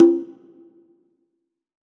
CONGA H1.wav